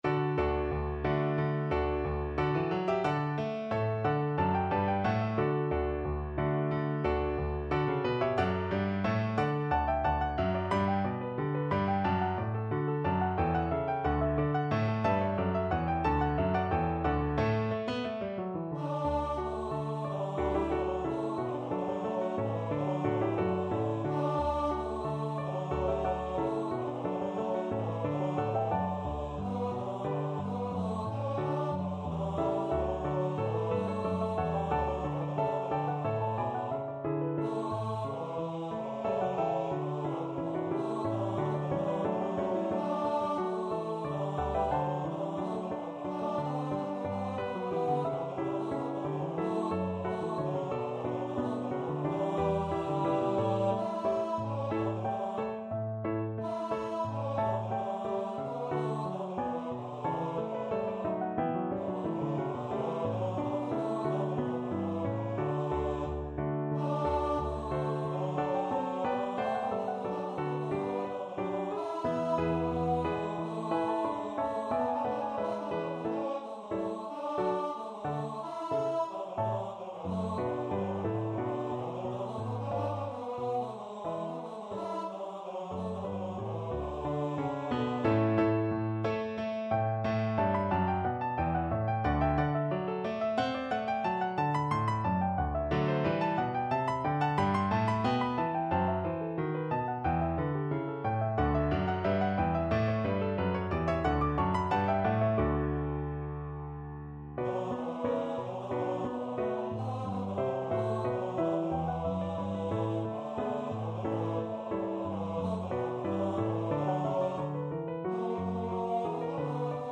Free Sheet music for Bass Voice
2/4 (View more 2/4 Music)
D major (Sounding Pitch) (View more D major Music for Bass Voice )
Bass Voice  (View more Advanced Bass Voice Music)
Classical (View more Classical Bass Voice Music)